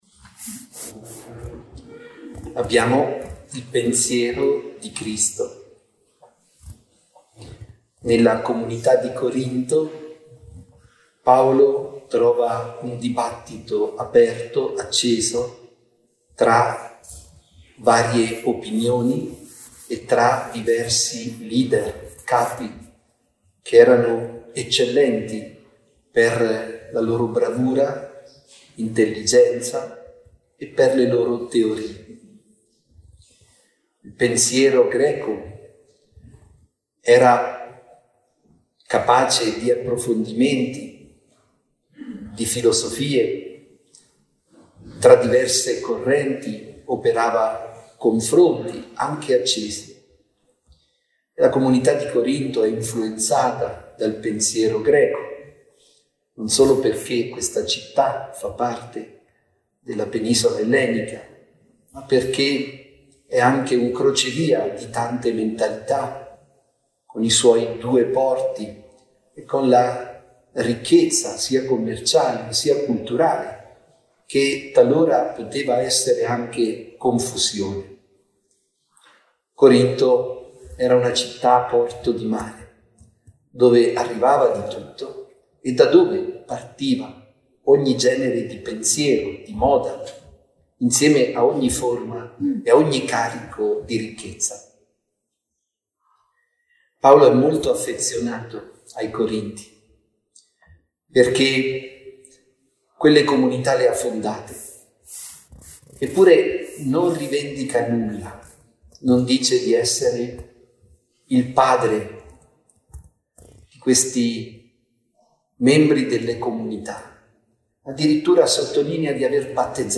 Meditazione-Vescovo-04mar24.mp3